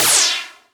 magic_dust_b.wav